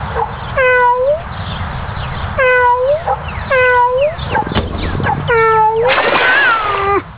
wetcat.wav